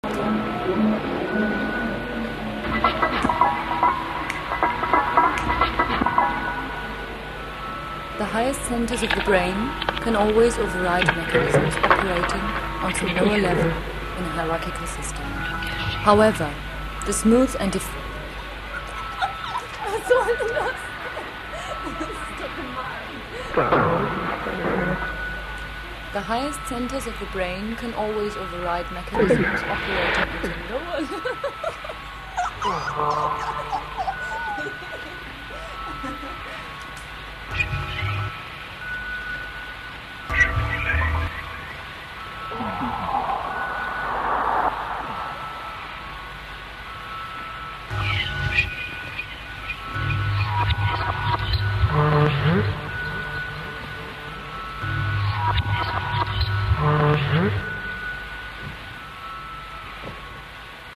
Klanginstallationen - Ausschnitte im mp3-Format